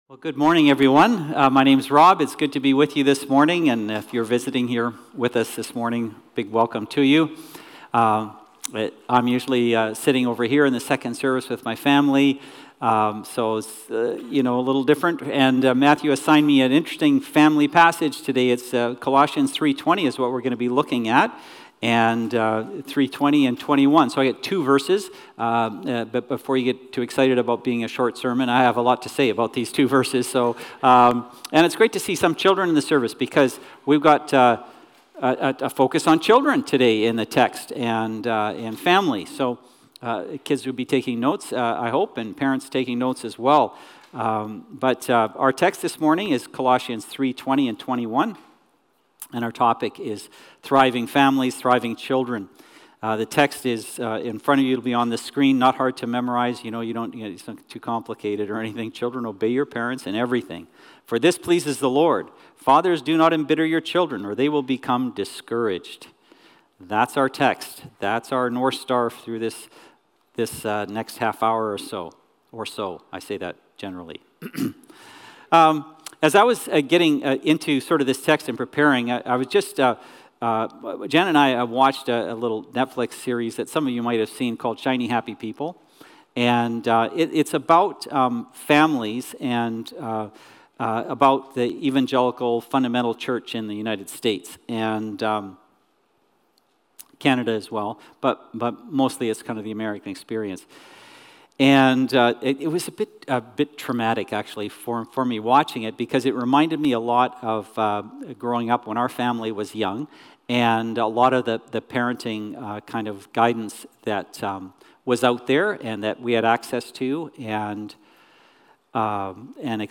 1 A Heart Set Free By Jesus Play Pause 3d ago Play Pause Play later Play later Lists Like Liked — We begin our new series with the story of the Rich Younger Ruler and his need for freedom. In this sermon we discover that vulnerability, honesty, and surrender are the keys to healing, as Jesusthe Good Doctorlovingly exposes what keeps us stuck and invites us into new life.